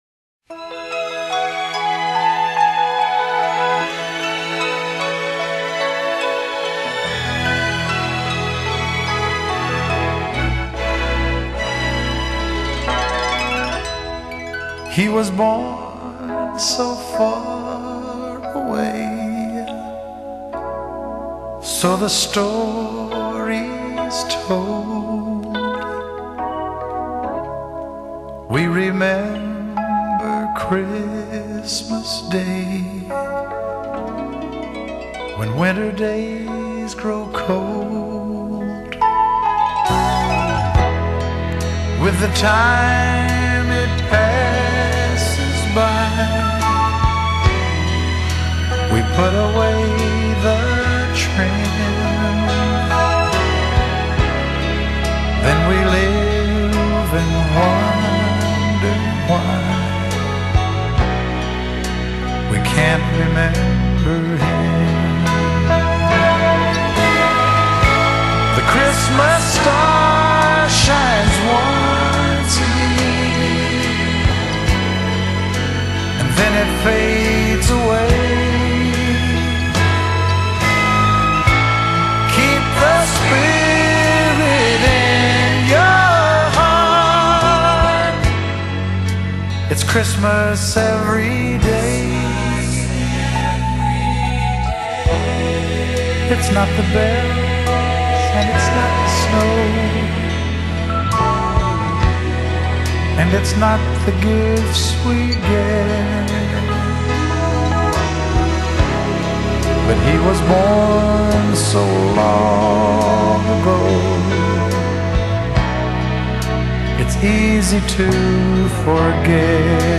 他的特點是能把鄉村音樂與流行音樂結合起來